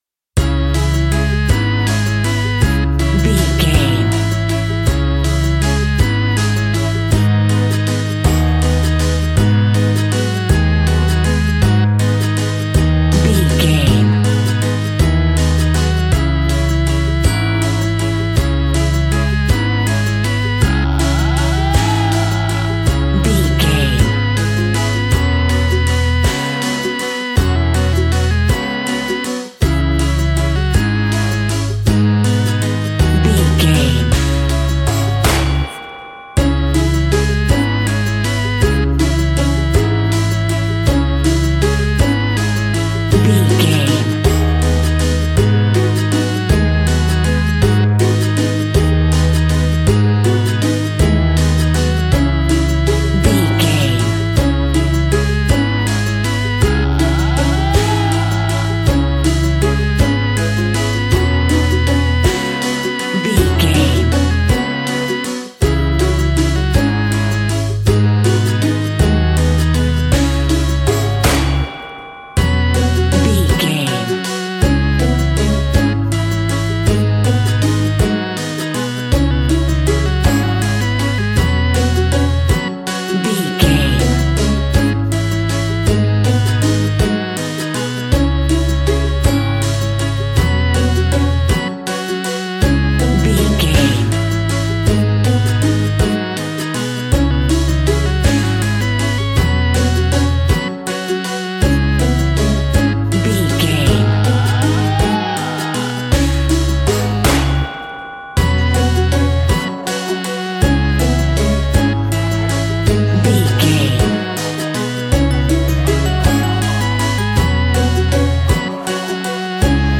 Aeolian/Minor
C#
ominous
eerie
acoustic guitar
percussion
strings
creepy
spooky